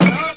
00408_Sound_bounce1
1 channel